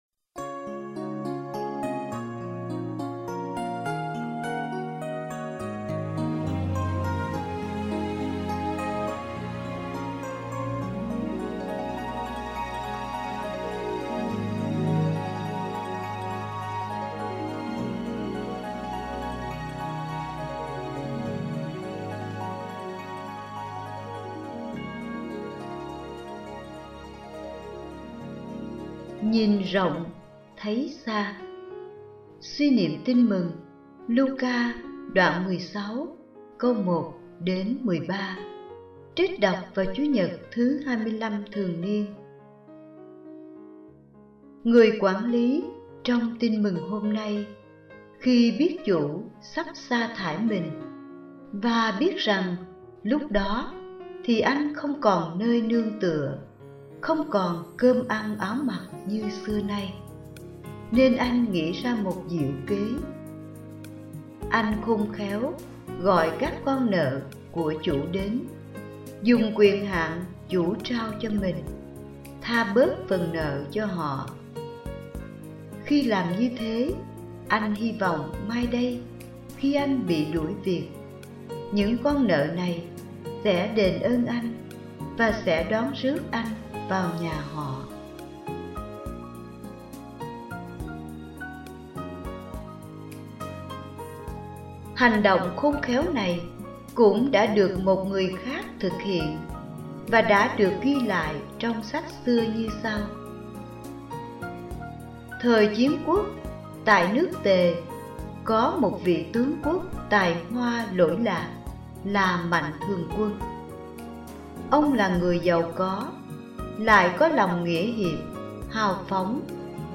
Suy niệm CN XXV TN C